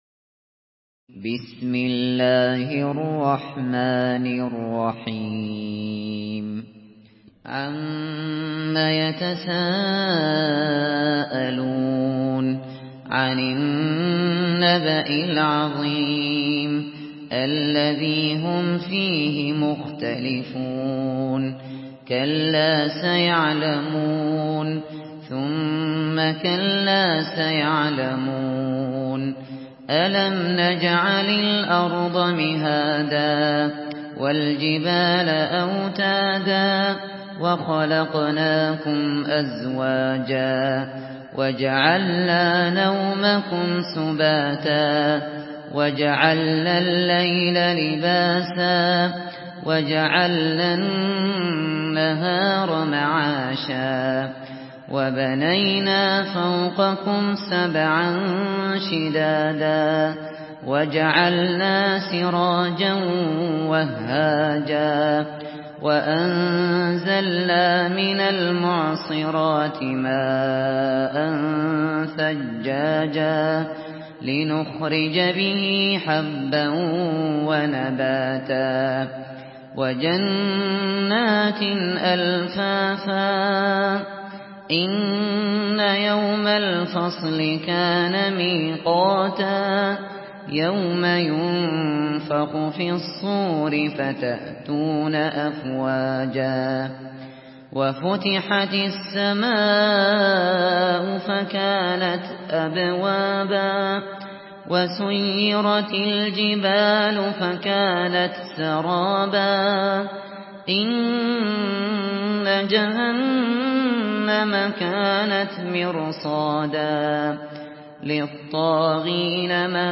تحميل سورة النبأ بصوت أبو بكر الشاطري
مرتل حفص عن عاصم